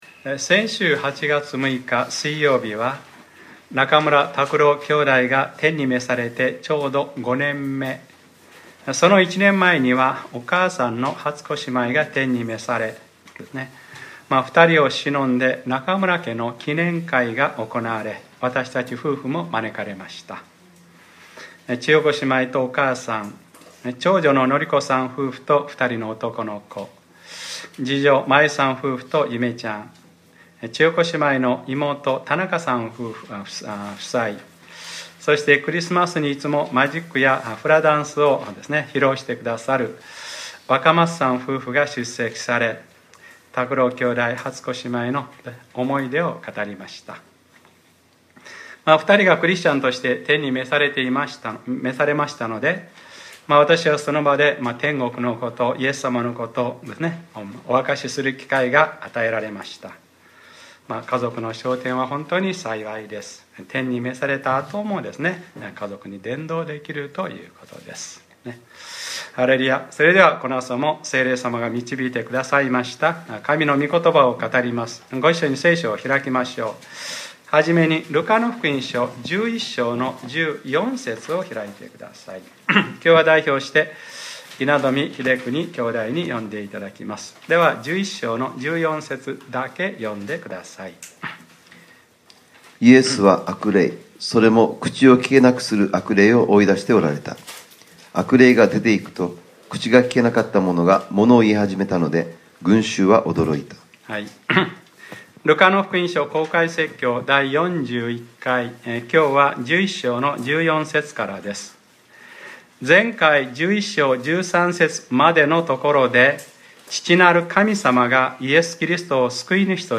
2014年8月10日（日）礼拝説教 『ルカｰ４２：わたしが神の指によって．．．』